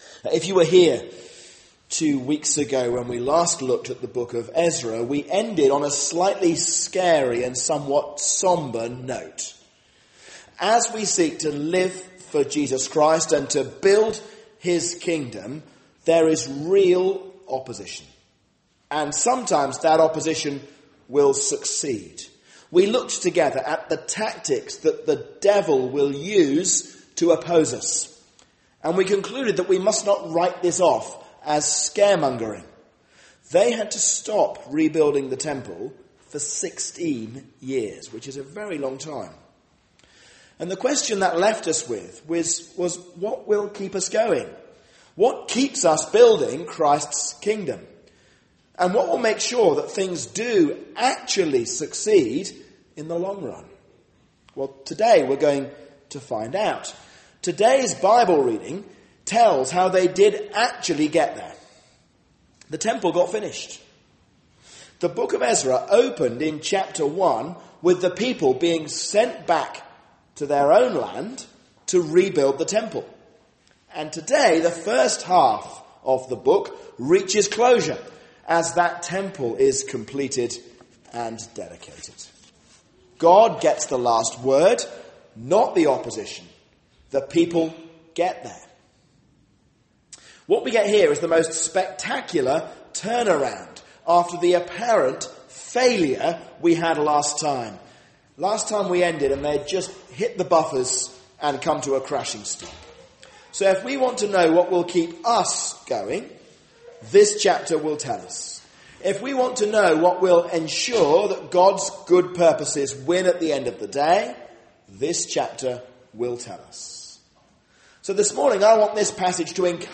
A sermon on Ezra 5-6